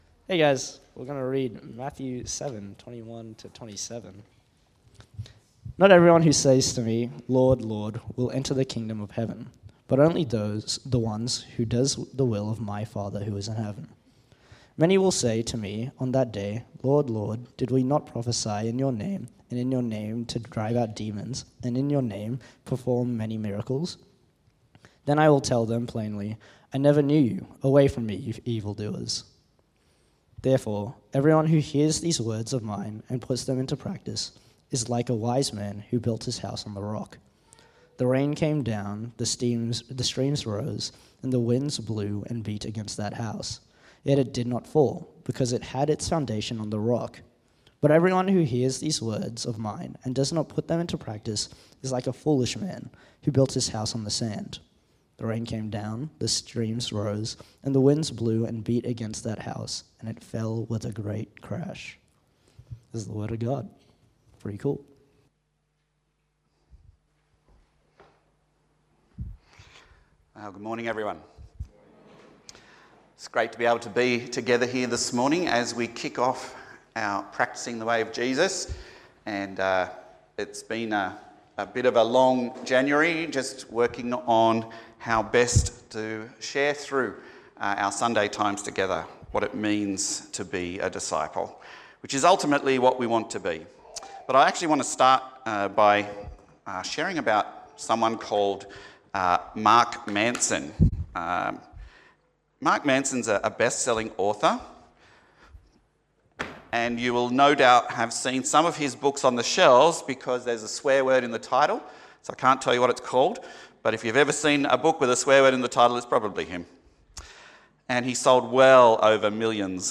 PennoBaps Sermons
Talks from Pennant Hills Baptist